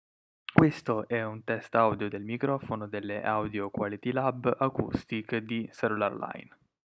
Ultimo ma non per importanza: il microfono non è male (forse un po’ cupo) e il tastino sul telecomando, se tenuto premuto, attiva l’assistente vocale.
– La qualità del micronofono non è eccelsa